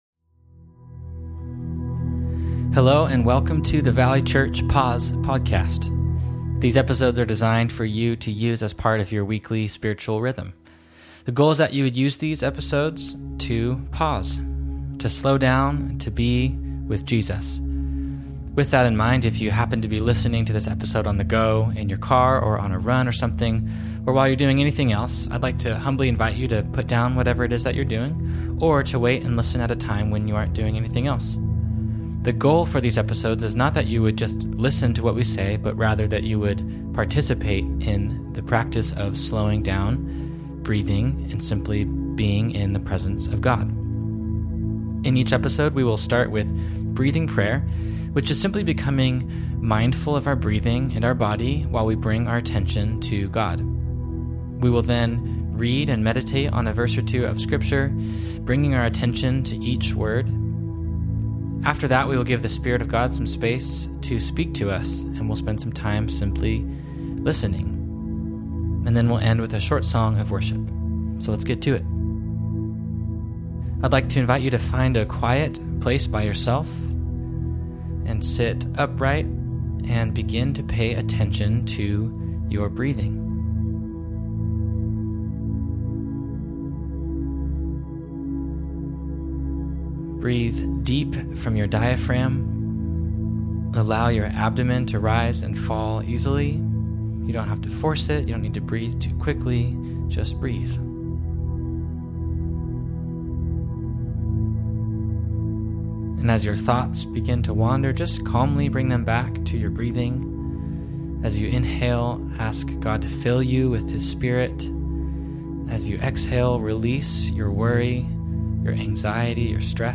Breathing prayer exercise adapted from Pete Scazzero’s Emotionally Healthy Relationships: Day by Day, Appendix B.